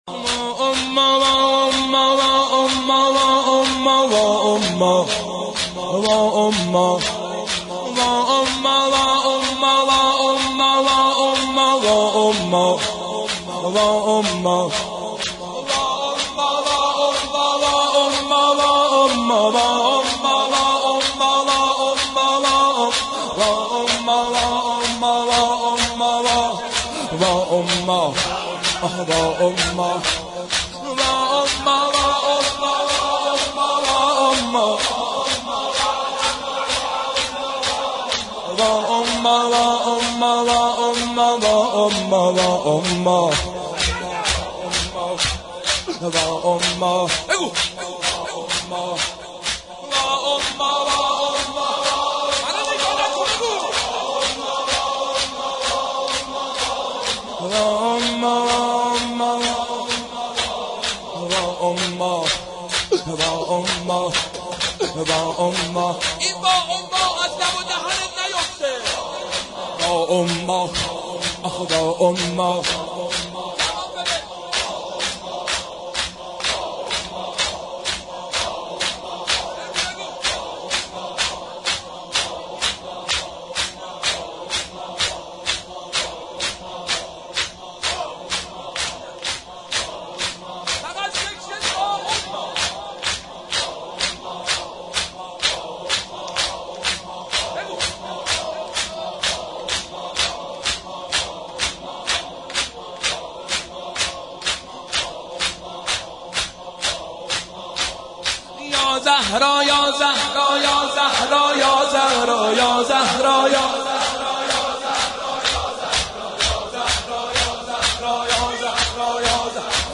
دانلود مداحی حالا که میروی به رویم خنده میکنی - دانلود ریمیکس و آهنگ جدید
[سینه زنی شور]